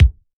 • Short Kick Drum Sound C# Key 247.wav
Royality free kick drum tuned to the C# note. Loudest frequency: 269Hz
short-kick-drum-sound-c-sharp-key-247-qrJ.wav